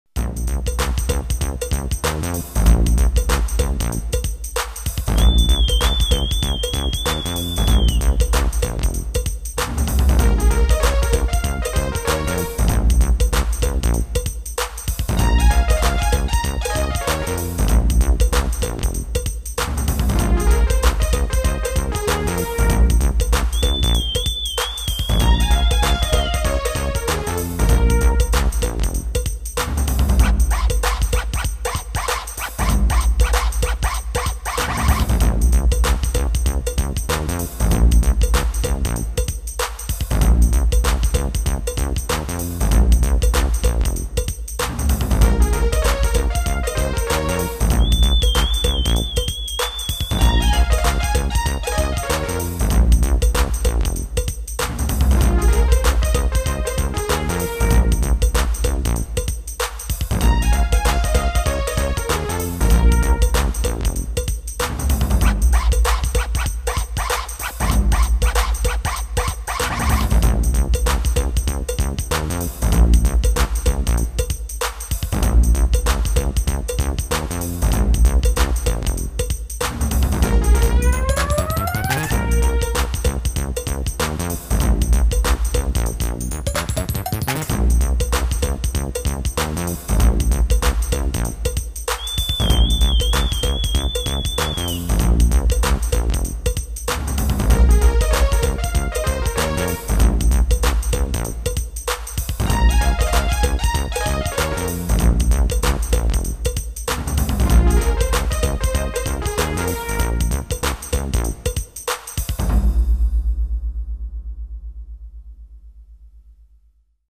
Roland MT-32 and Sound Canvas Enhanced version.